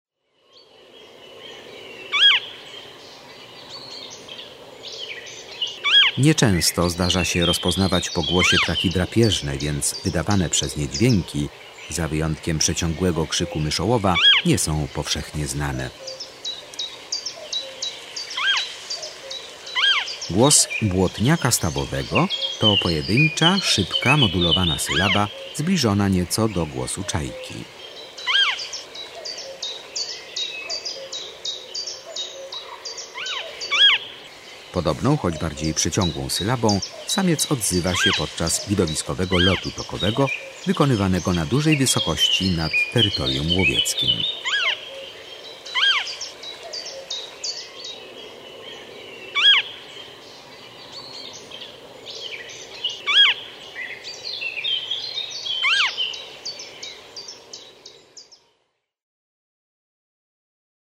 07 Błotniak stawowy.mp3